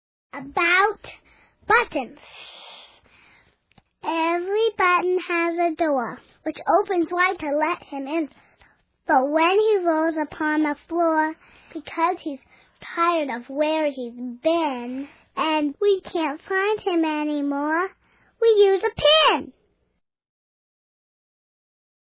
It's a collection of poems performed by kids, but For Kids By Kids is good for everybody big and little. Listeners will grin from ear to ear over the mispronounced words, the imaginative language and the expressiveness of each kid's unique voice.